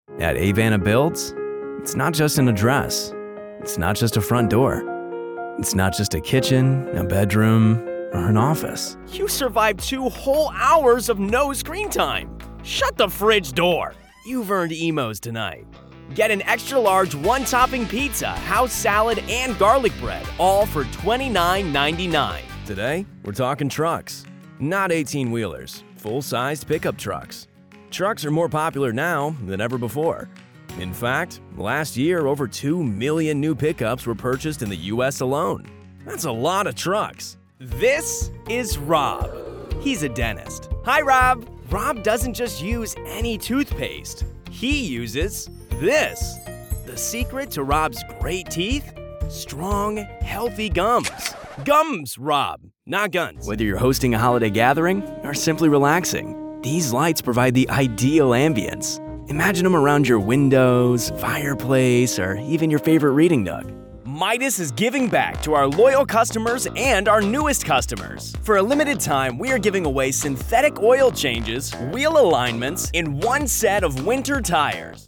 Professional Commercial Demo Reel
All North-American Accents, Aristocratic British, General European
Professional Commercial Reel #2.mp3